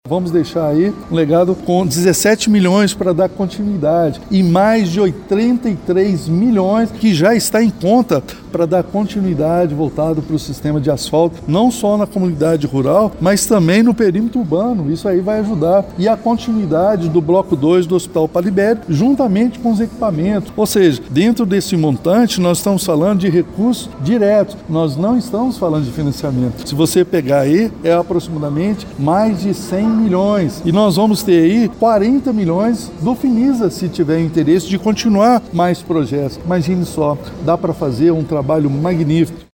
Em coletiva de imprensa, no Teatro Municipal, o Prefeito Elias Diniz fez uma apresentação das principais ações da administração municipal em 2024.